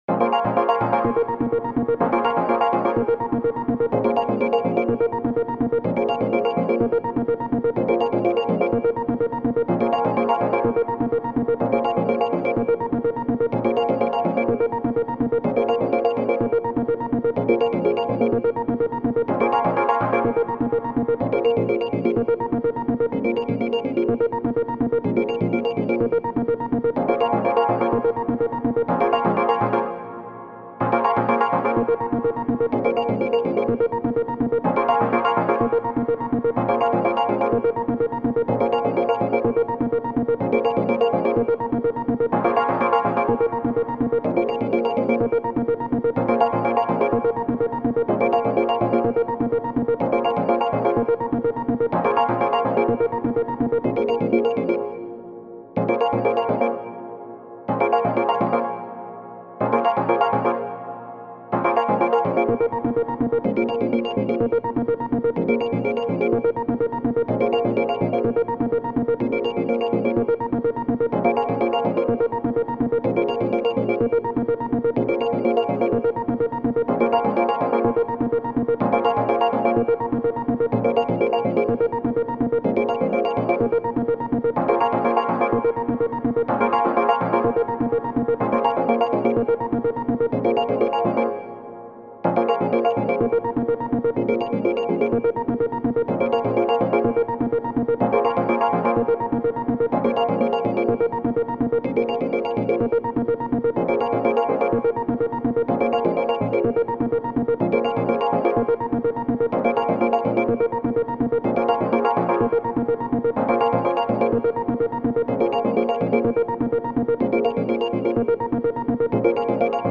【用途/イメージ】ニュース　メディア　ドキュメント　報道　ナレーション　緊急　事件